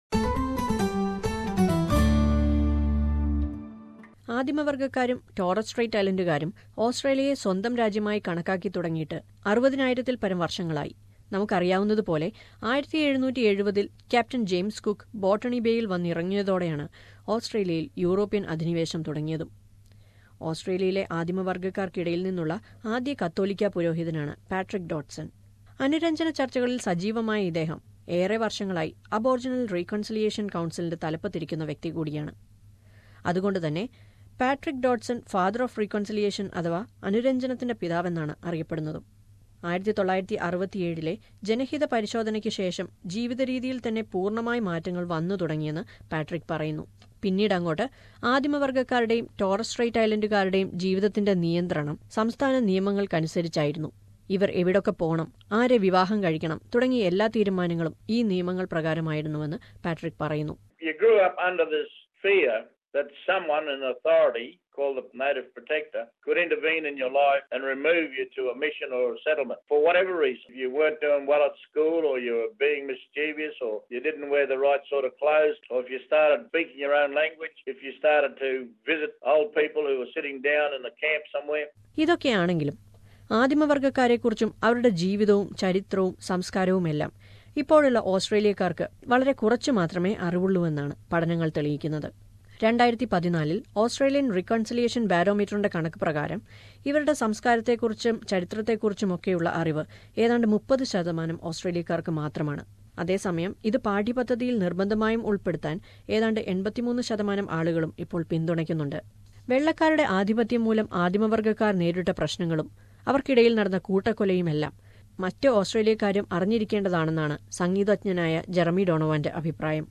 Its national reconciliation week in Australia. during this time its important to share the history and culture of aboriginal people to non-aboriginal people. Listen to a report on this.